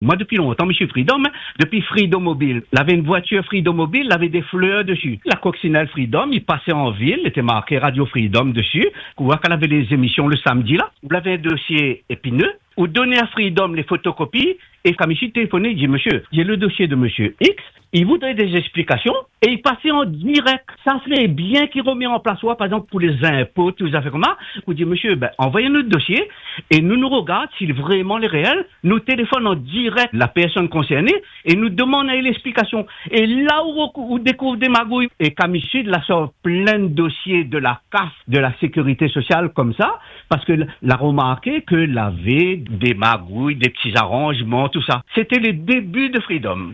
Radio Free Dom, défenseur des Réunionnais depuis ses débuts. Ce matin, un auditeur replonge dans les premières années de la radio… et son témoignage respire la nostalgie.
À travers cet appel, c’est toute une époque qui refait surface.